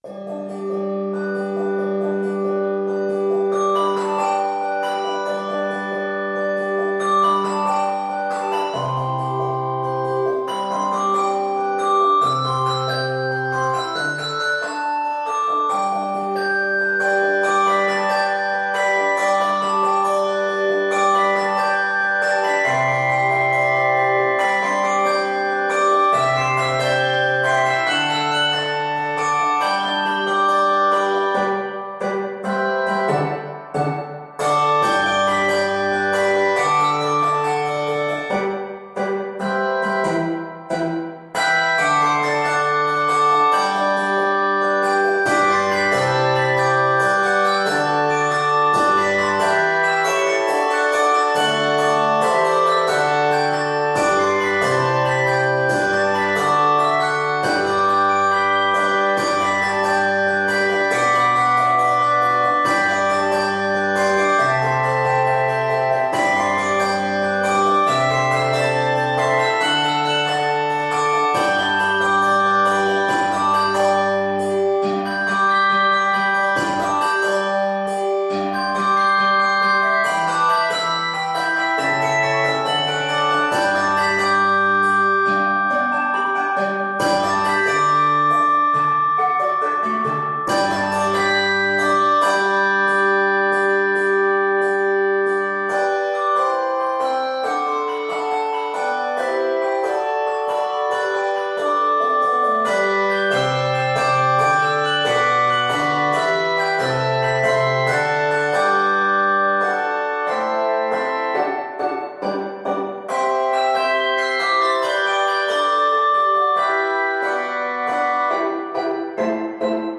is set in G Major